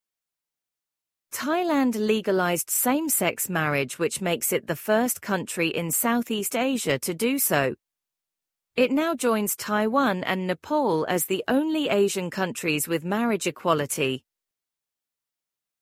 Try other AI voices